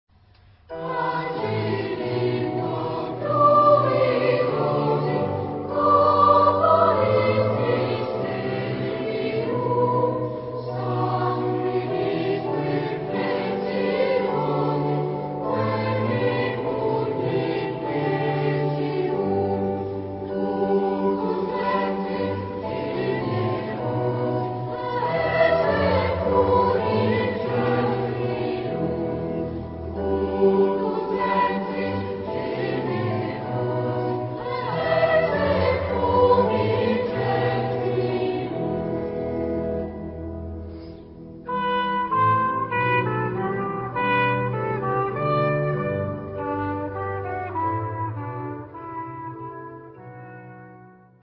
Genre-Stil-Form: geistlich ; Barock ; Hymnus (geistlich)
Chorgattung: SATB  (4 gemischter Chor Stimmen )
Instrumentation: Instrumentalensemble  (5 Instrumentalstimme(n))
Instrumente: Violinen (2) ; Viola (1) ; Violoncello (1) ; Orgel (1)
Tonart(en): e-moll